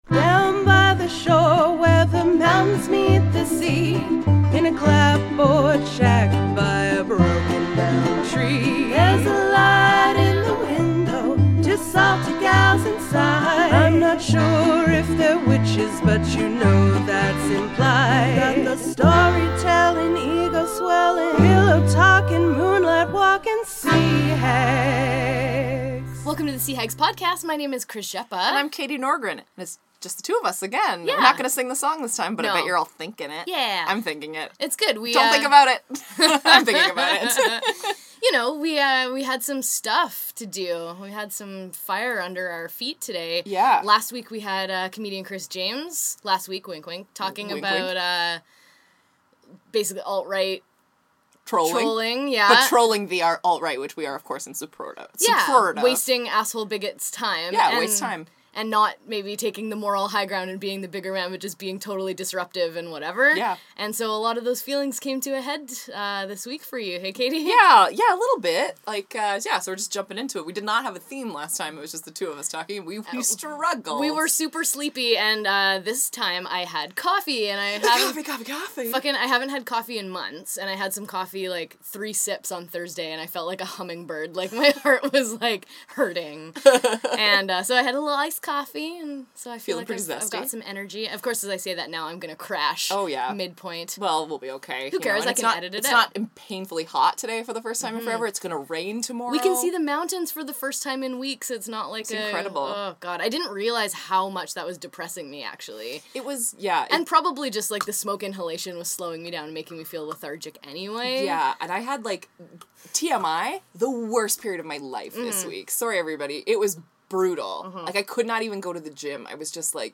A surprising chick lit bait-and-switch inspires a fiery convo between your hosts about standing up for yourself and others, and the space that’s freed up by no longer giving a fuck. No guest, but a whole lot of rustled jimmies.